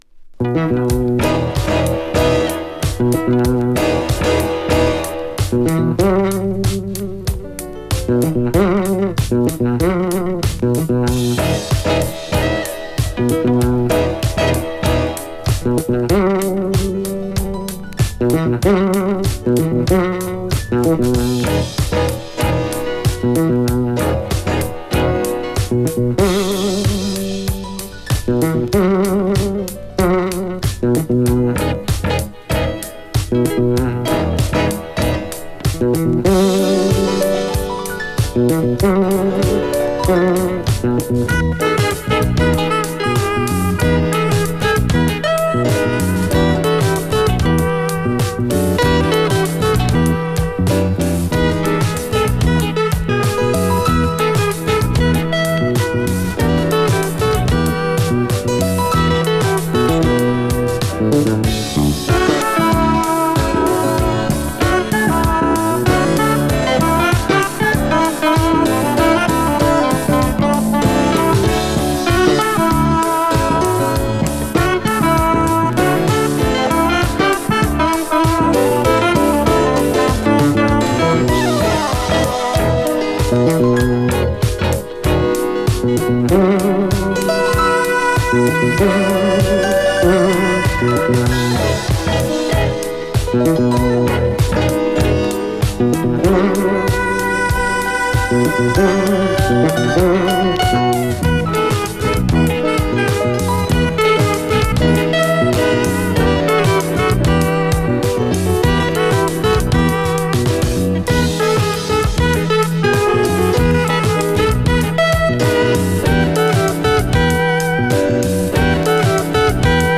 > DANCE CLASSICS/GARAGE